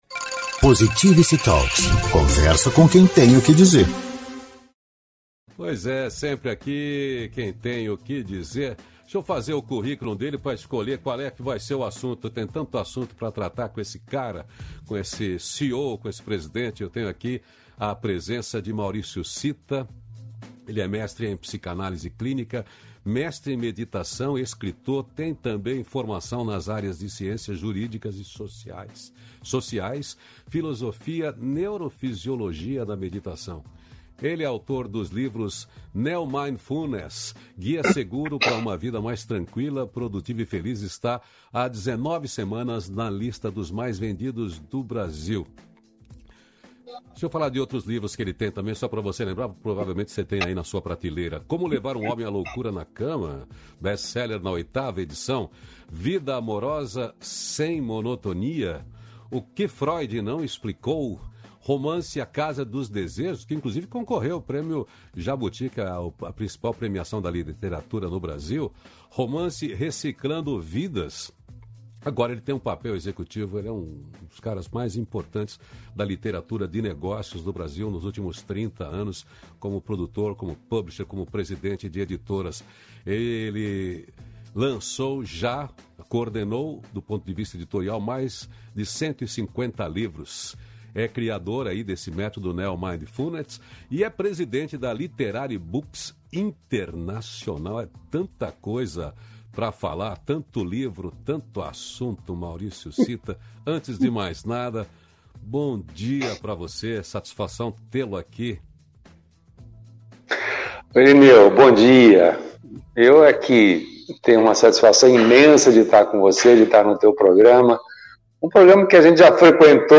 316-feliz-dia-novo-entrevista.mp3